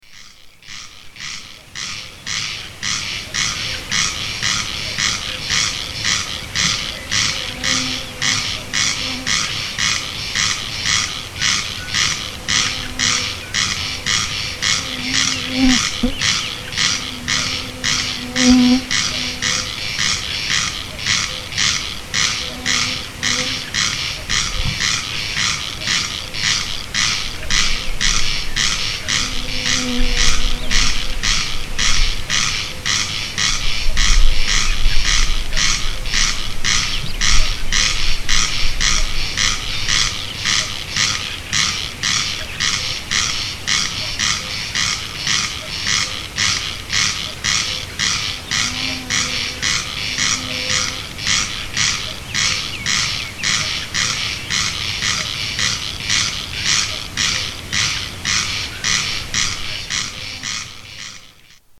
Solstice Frogs